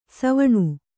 ♪ savaṇu